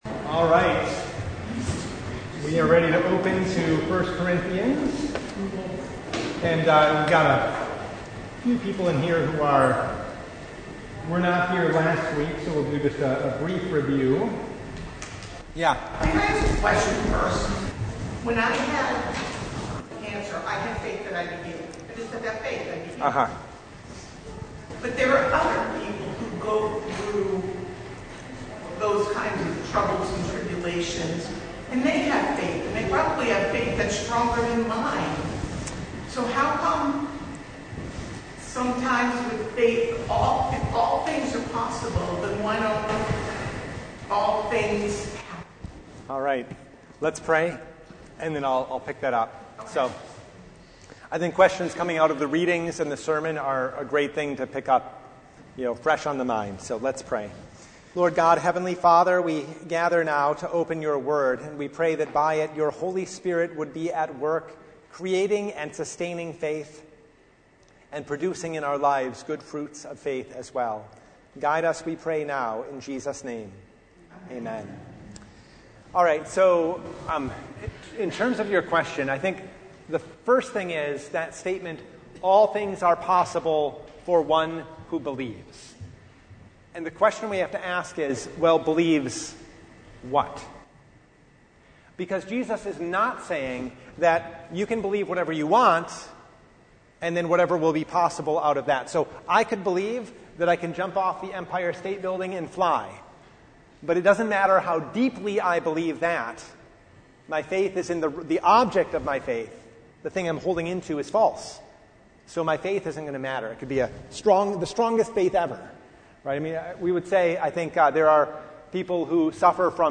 1 Corinthians 1:10-17 Service Type: Bible Hour Topics: Bible Study « The Sixth Sunday in Martyrs’ Tide